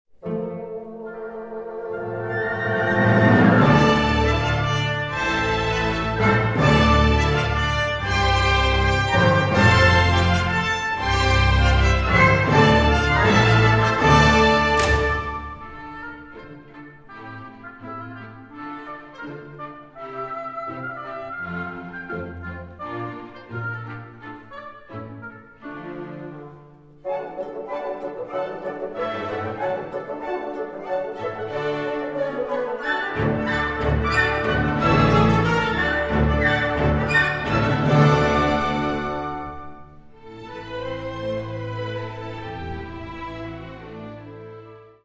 First Recordings made in the Presence of the Composer